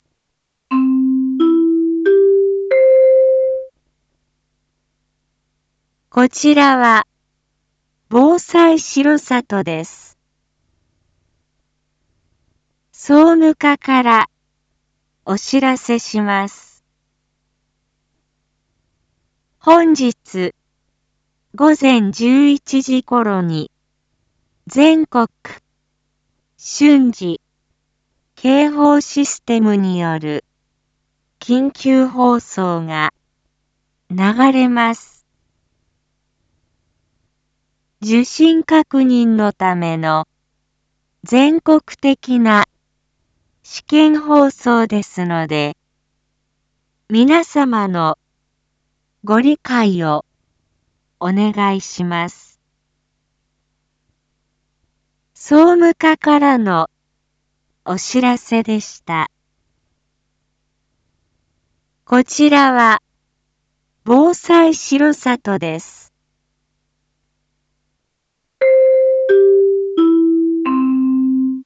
Back Home 一般放送情報 音声放送 再生 一般放送情報 登録日時：2023-07-12 07:01:18 タイトル：Jアラート全国一斉伝達訓練について インフォメーション：こちらは、防災しろさとです。